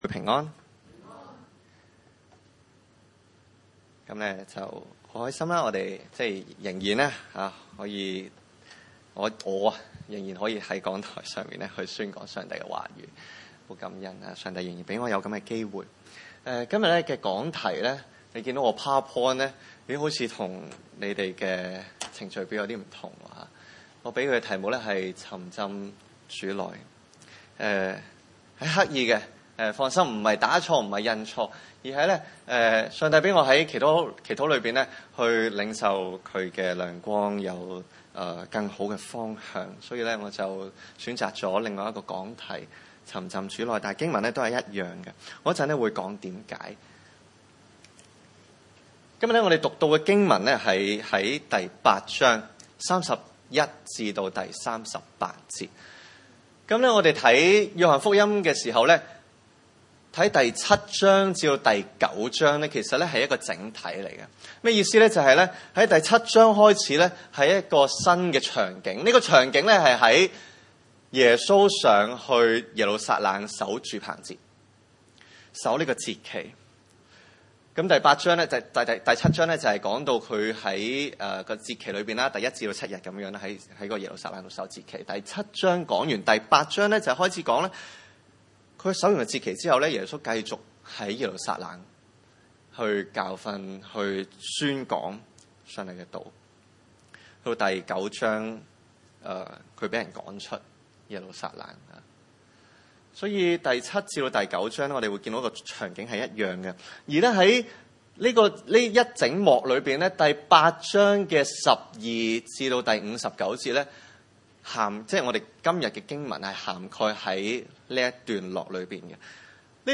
經文: 約翰福音8 : 31-38 崇拜類別: 主日午堂崇拜 8:31 耶 穌 對 信 他 的 猶 太 人 說 、 你 們 若 常 常 遵 守 我 的 道 、 就 真 是 我 的 門 徒 。